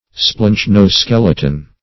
Search Result for " splanchno-skeleton" : The Collaborative International Dictionary of English v.0.48: Splanchno-skeleton \Splanch`no-skel"e*ton\, n. [Gr.